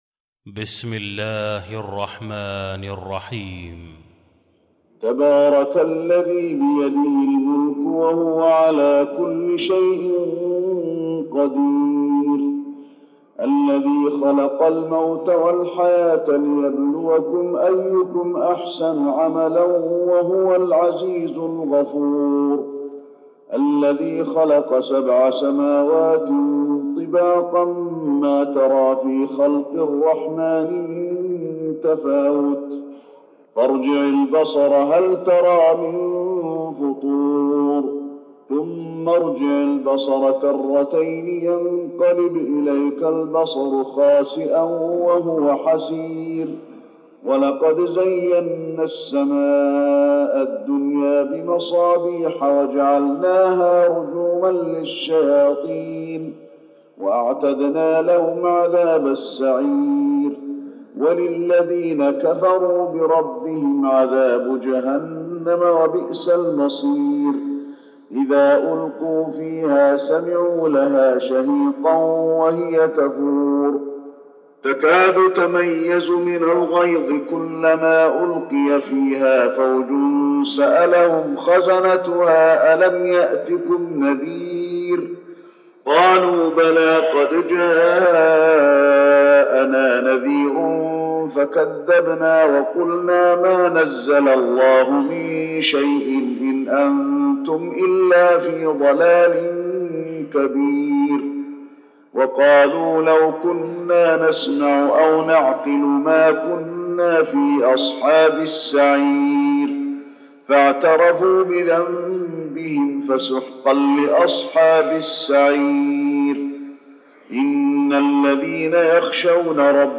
المكان: المسجد النبوي الملك